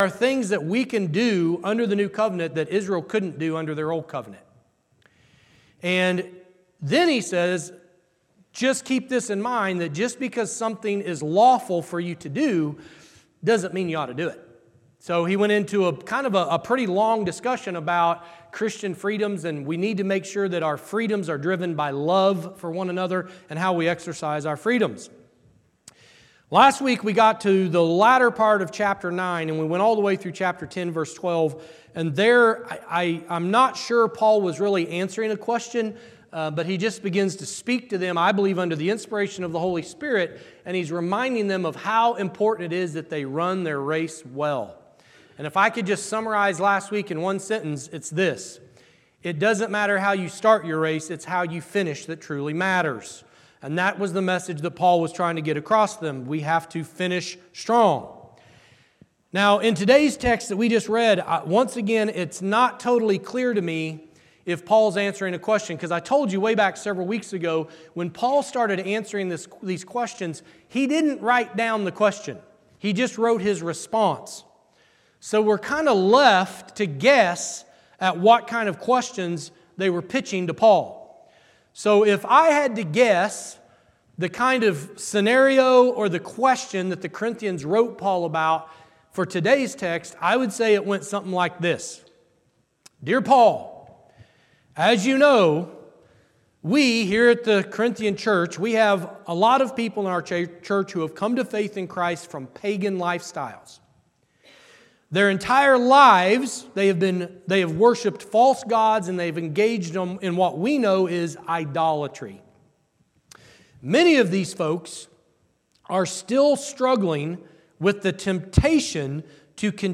Church in Action Sermon Podcast December 14, 2025 Play Episode Pause Episode Mute/Unmute Episode Rewind 10 Seconds 1x Fast Forward 30 seconds 00:00 / 30:59 Subscribe Share Spotify RSS Feed Share Link Embed